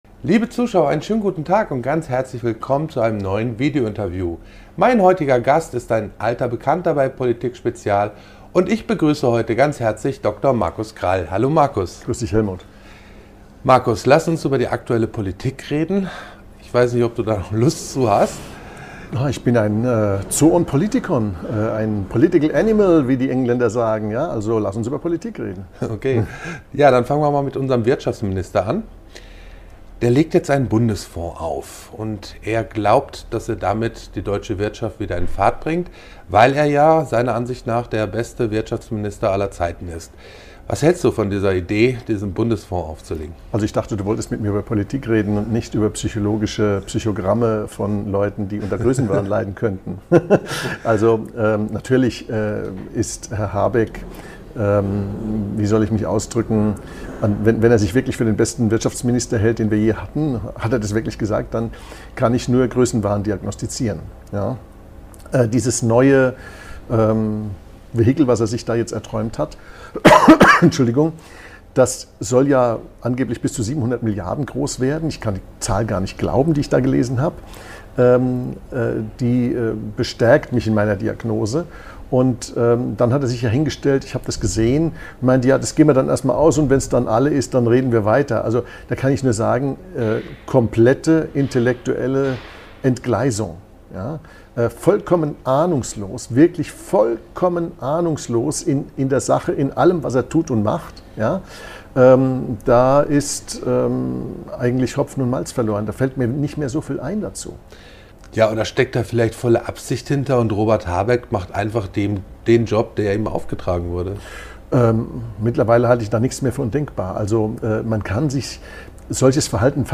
Ein aufschlussreiches Interview über notwendige Reformen des Steuer- und Sozialsystems sowie die fundamentale Frage nach den Kernaufgaben des Staates. Das Gespräch wurde im Rahmen unseres Kapitaltags am 25. Oktober 2024 aufgezeichnet.